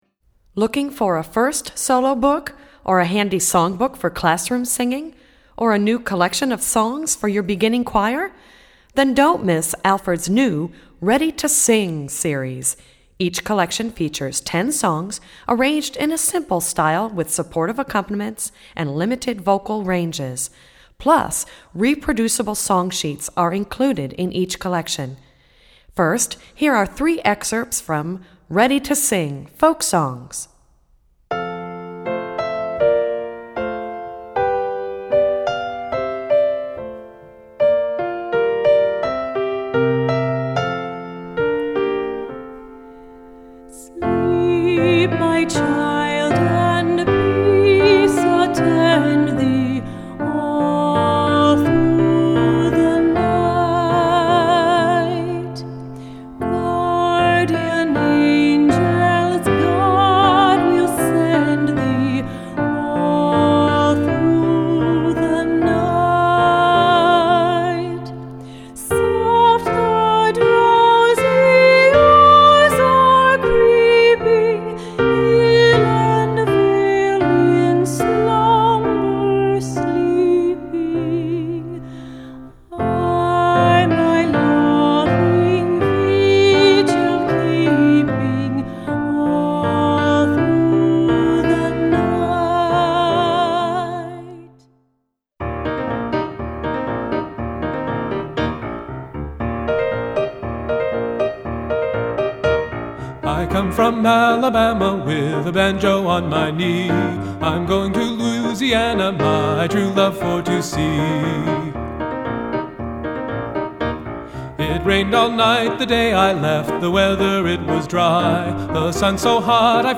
Voicing: Vocal Collection